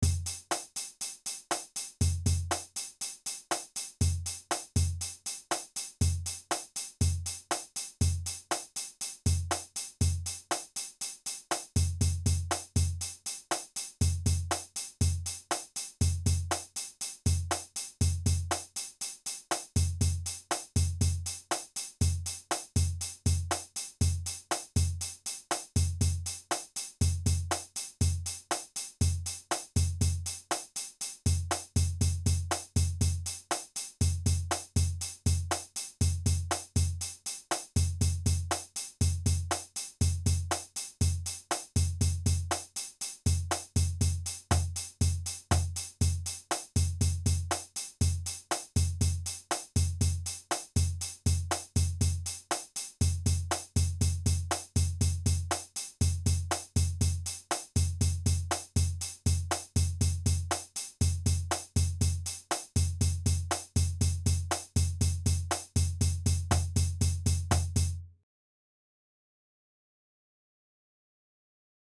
‼ Eighth-Note Groove Study:
32+ Standard Eighth-Note Grooves
32grooves.mp3